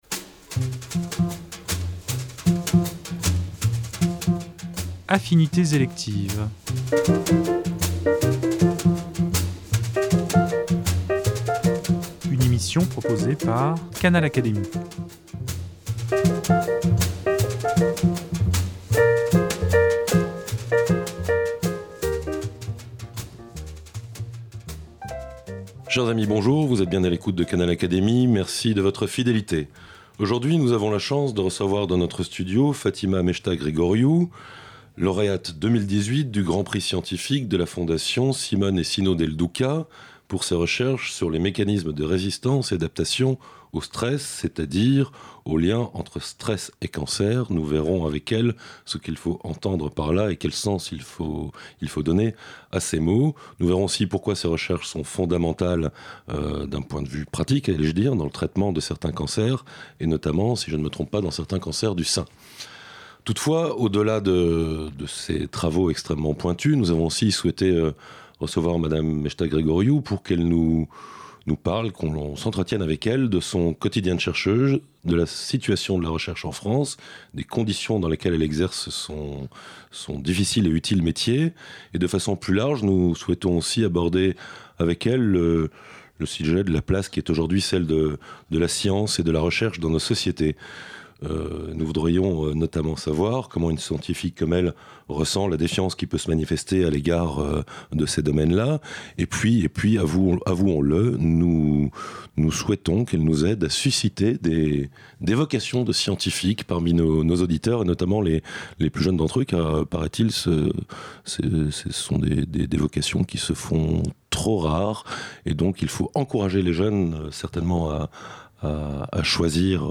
Outre ses recherches, elle évoque, dans cet entretien inédit, sa carrière, la naissance de sa vocation, les liens entre recherches fondamentale et appliquée, ainsi que le quotidien des chercheurs.